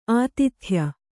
♪ ātithya